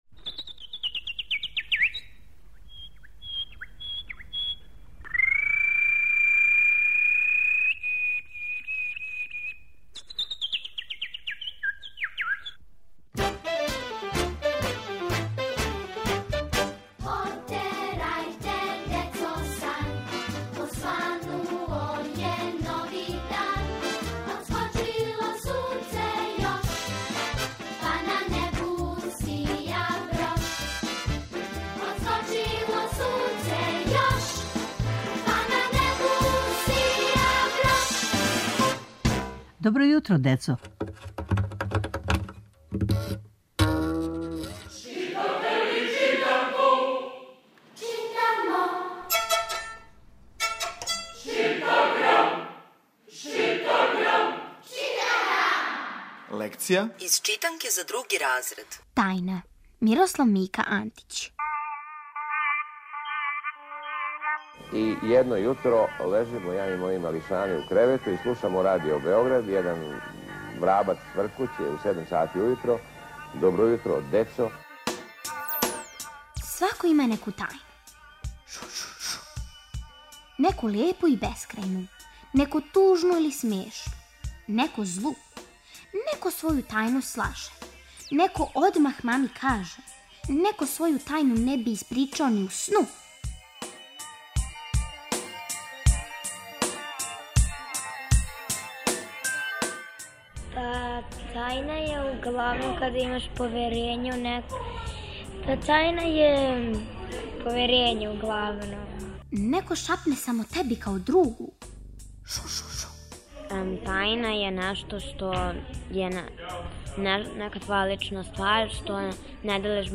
Читанка за слушање. Ове недеље - други разред, лекција: "Тајна", песма Мике Антића.